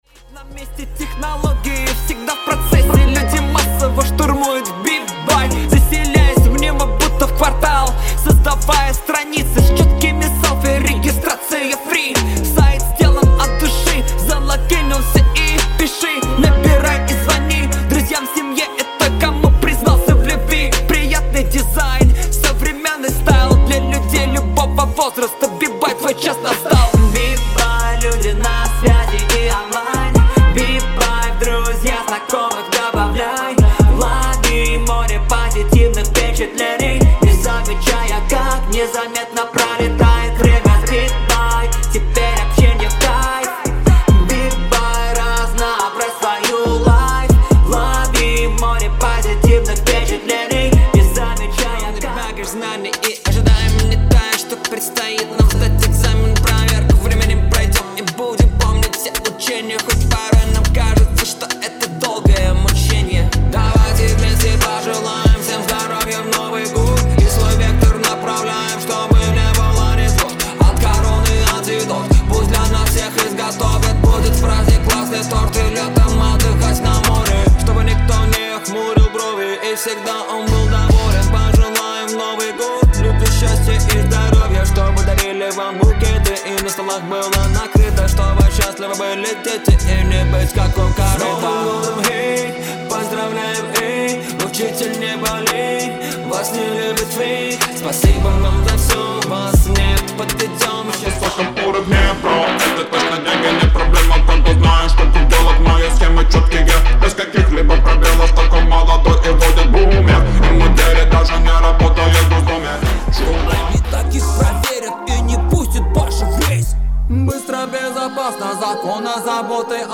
Муж, Рэп/Средний
Rode NT1-A, focusrite scralett solo 2nd gen, Cubase 11 pro.